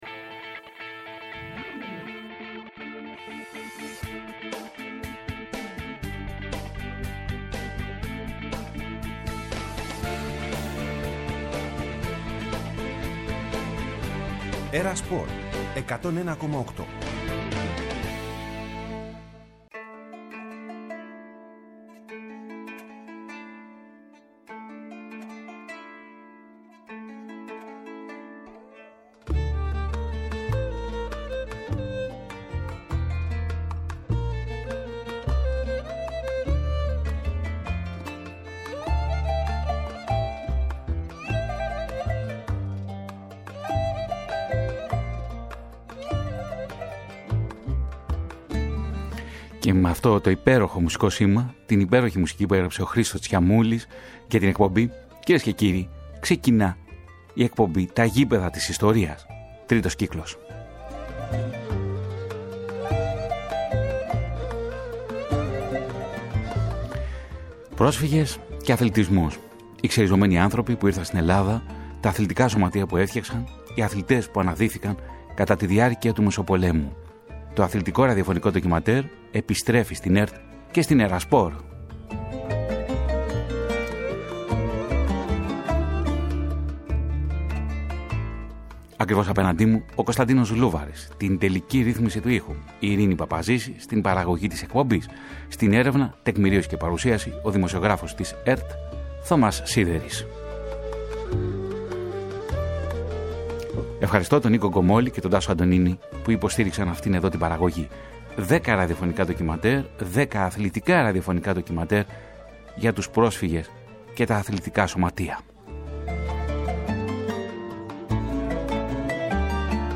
Πρόκειται το πρώτο από δέκα ραδιοφωνικά ντοκιμαντέρ για την εγκατάσταση των προσφύγων στην Ελλάδα και την ίδρυση αθλητικών σωματείων και συλλόγων.